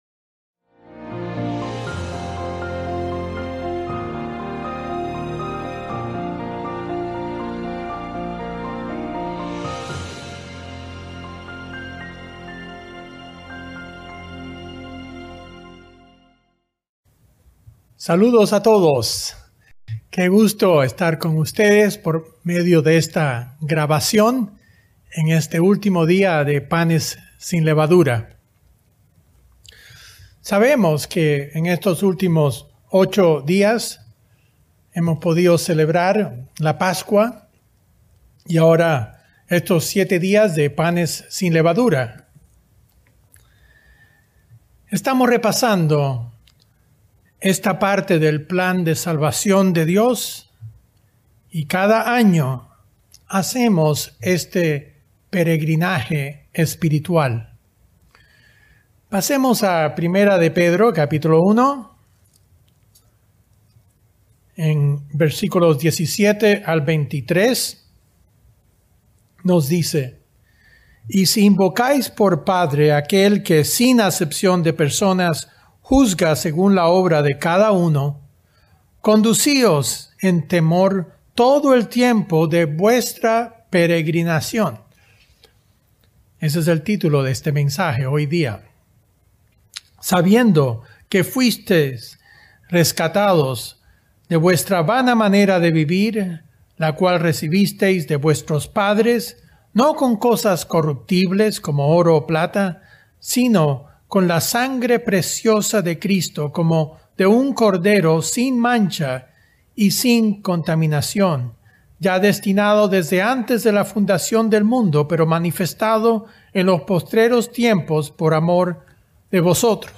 Mensaje entregado el 3 de abril de 2021.